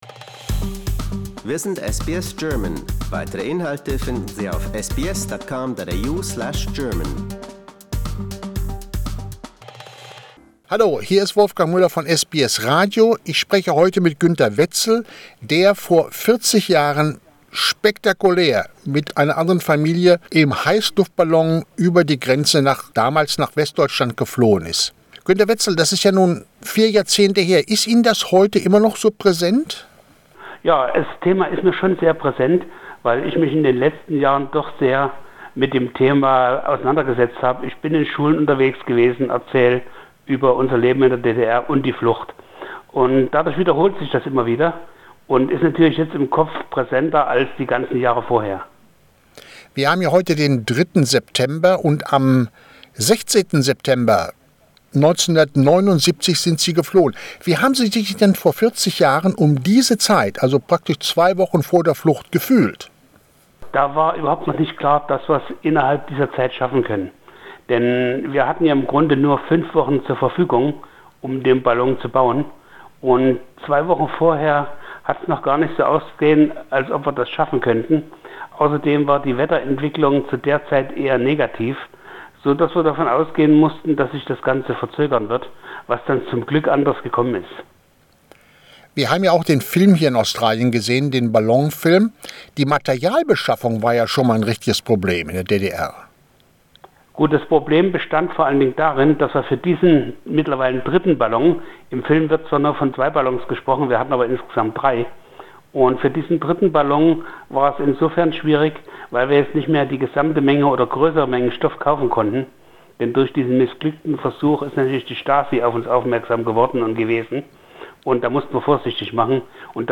Today: An interview